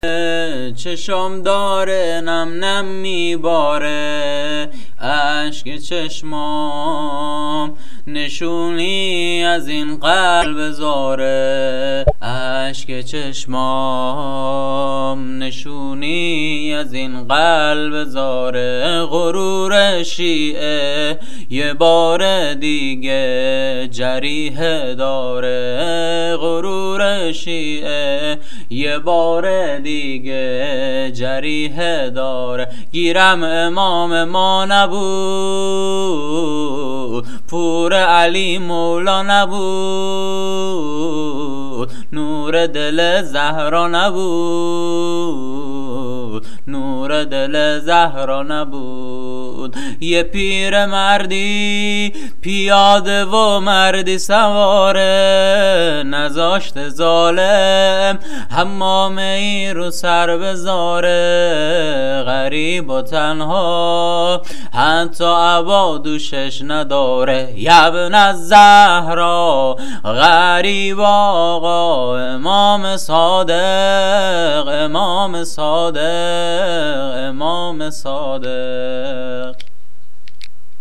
زمینه شهادت امام صادق
سبک سینه زنی
سبک مداحی